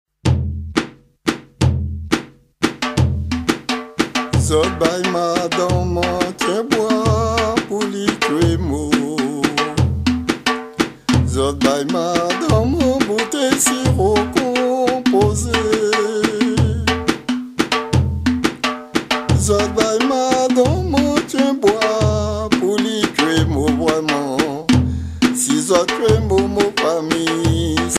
danse : grajé (créole)
Pièce musicale inédite